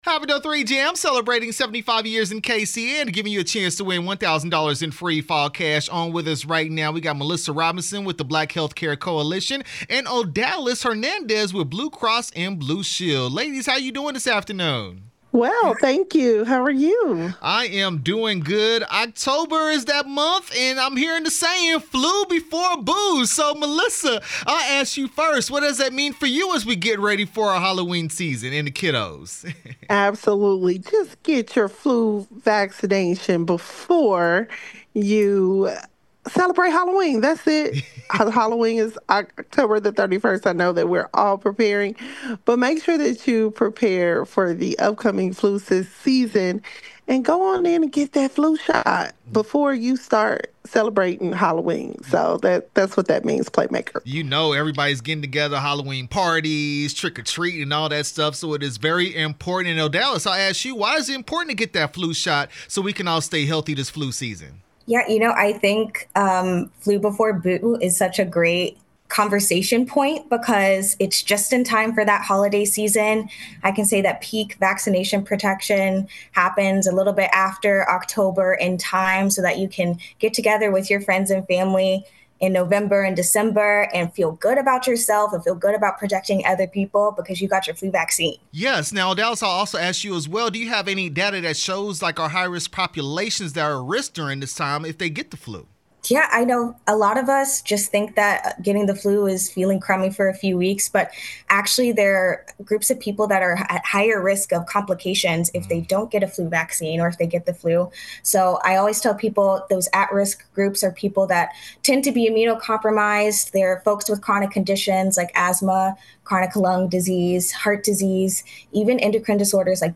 Black Health Care Coalition/Blue Cross Blue Shield of KC interview 10/1/25